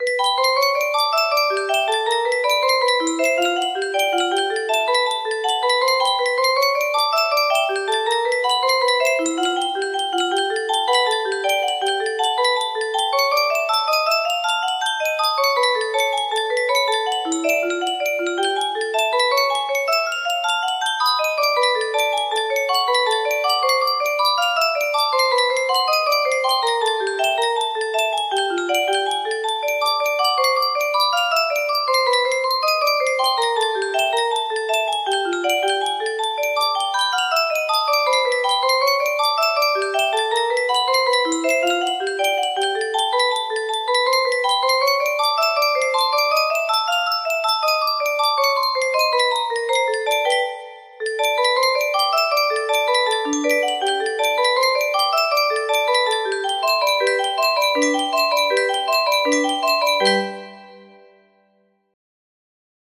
butterfly music box melody
Grand Illusions 30 (F scale)